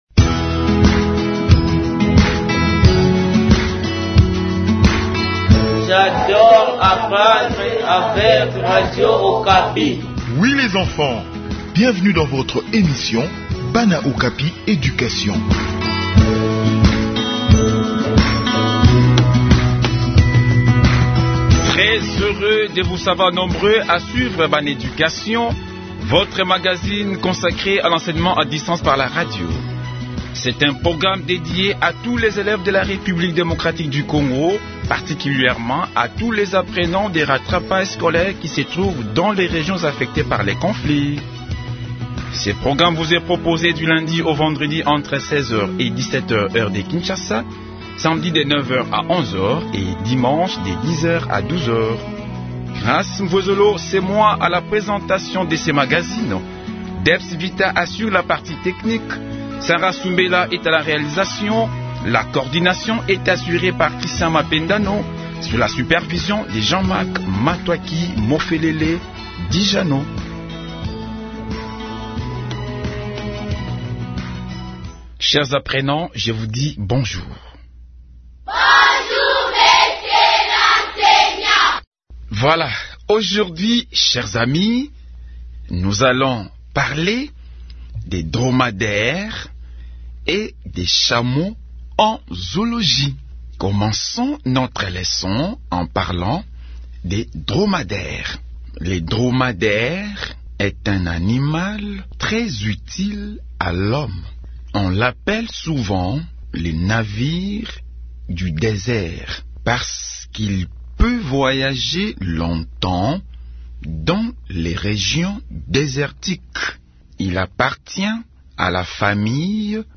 Enseignement à distance : leçon de zoologie sur le dromadaire